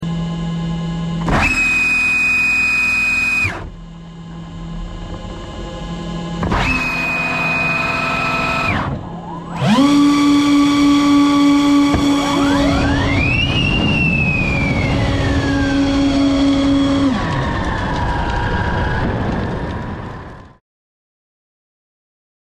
Worlds fastest accelerating electric vehicle! sound effects free download
Onboard run with data overlay.